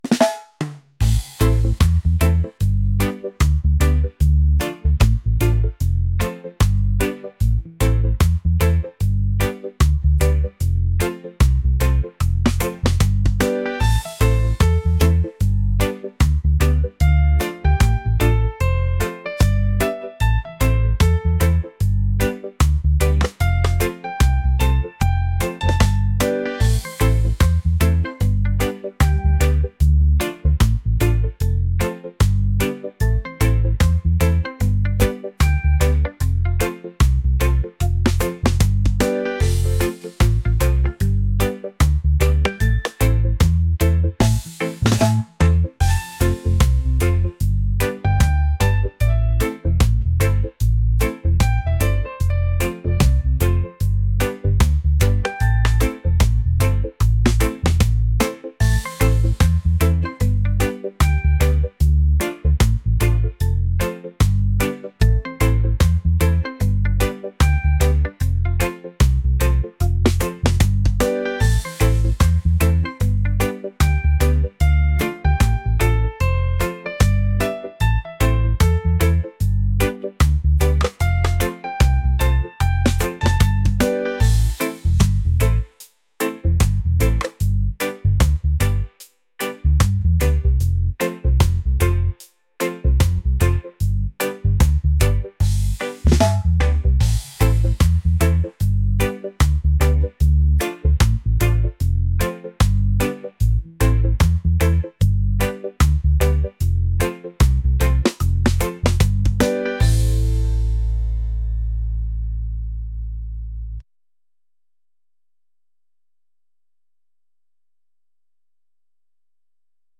laid-back | upbeat | reggae